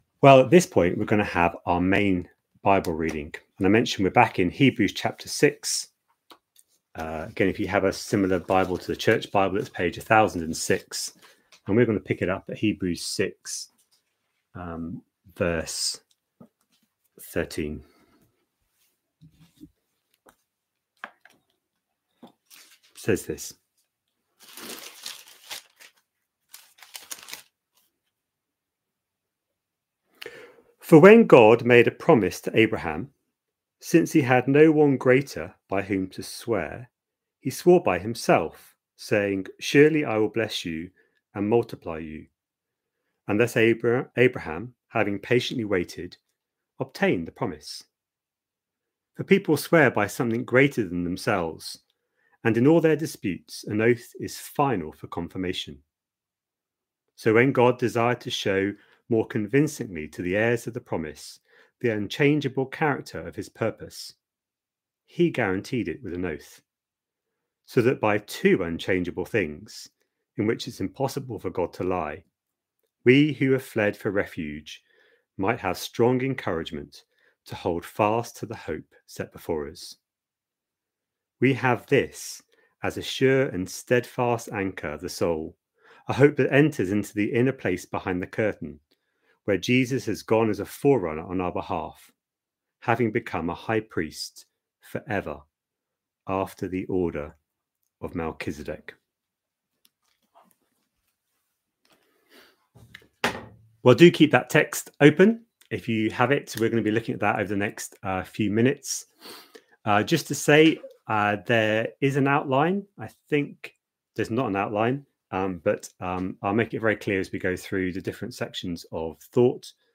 A sermon preached on 5th January, 2025, as part of our Hebrews 24/25 series.